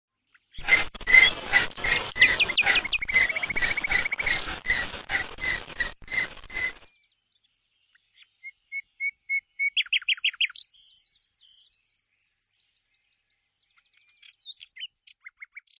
Igel
Das Pfauchen und Schreien eines Igels kann besonders in der Paarungszeit sehr laut sein. Das stachelige Säugetier ist in Europa weit verbreitet und häufig auch in Siedlungen anzutreffen.
igel.mp3